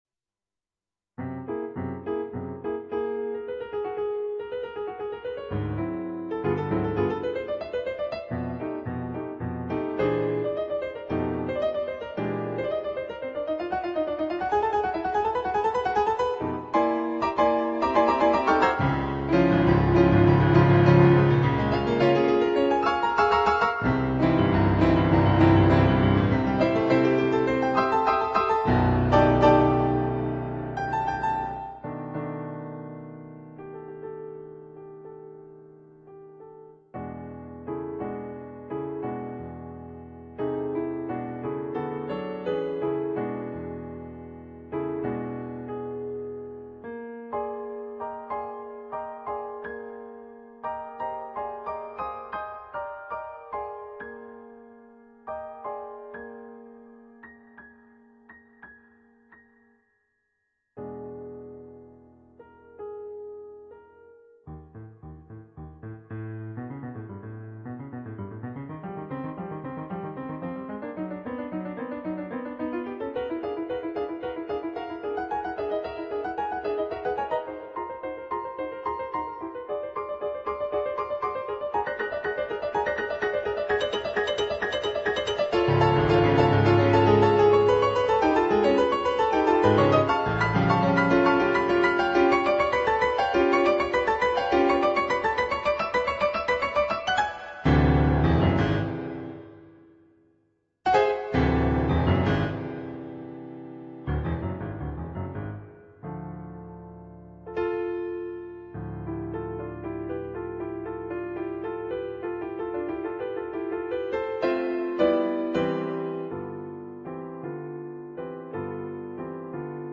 Allegro commodo, burlesco (2'24")
on Yamaha digital pianos.